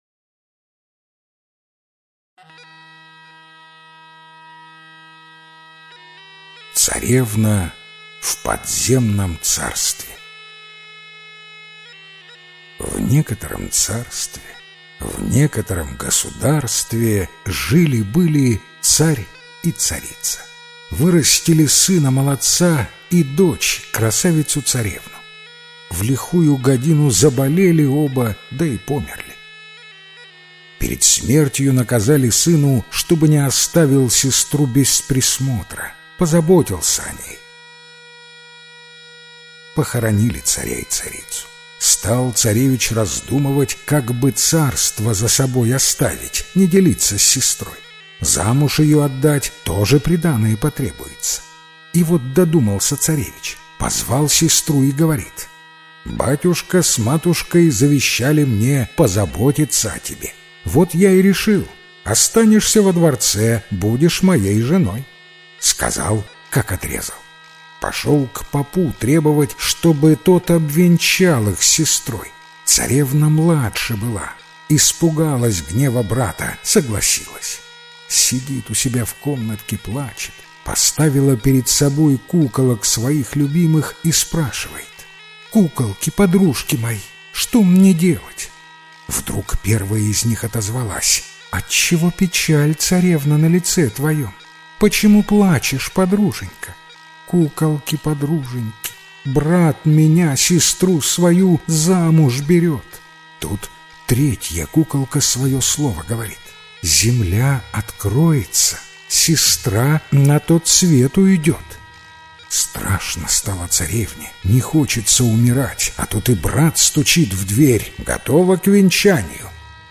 Царевна в подземном царстве - белорусская аудиосказка. Умерли царь и царица. Остались у них сын и дочь.